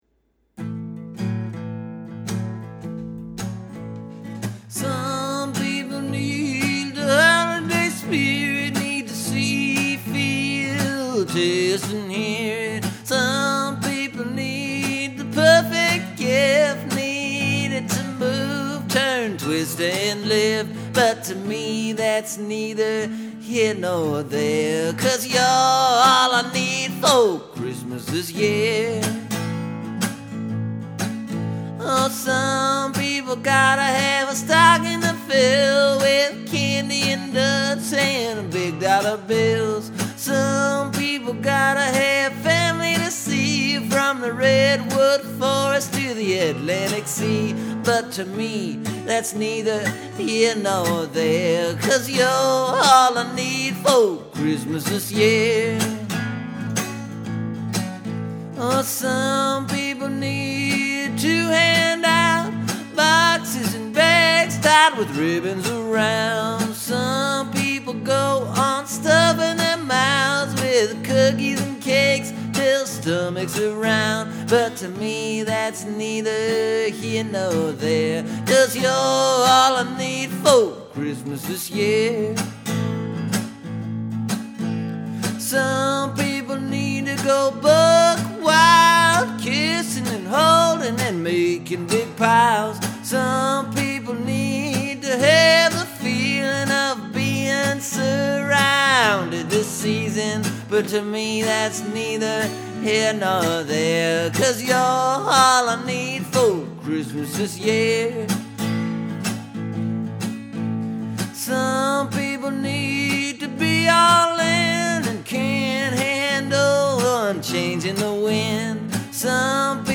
Last year I guess I was feeling some Holiday Spirit and went and wrote a Christmas song.
You’ll notice I mixed up the repeating element a bit, as well as a few words and lines here and there.